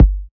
SOUTHSIDE_kick_fast_pace.wav